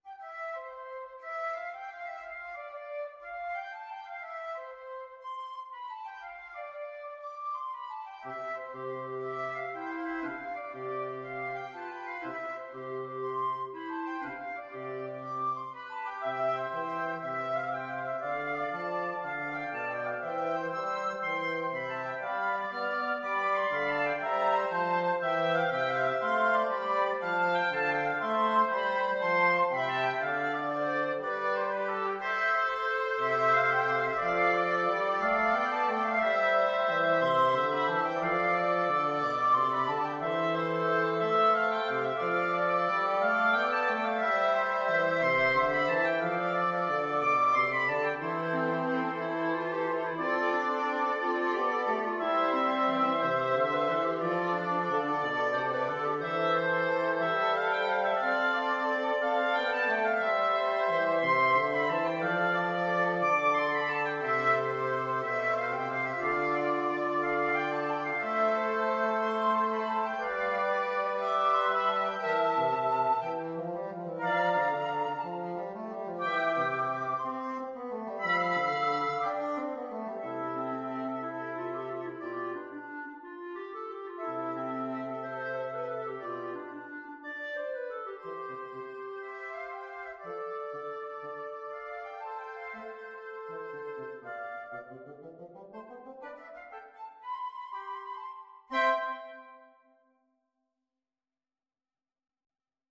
Free Sheet music for Wind Quartet
FluteOboeClarinetBassoon
6/8 (View more 6/8 Music)
C major (Sounding Pitch) (View more C major Music for Wind Quartet )
Allegro moderato . = 120 (View more music marked Allegro)
Classical (View more Classical Wind Quartet Music)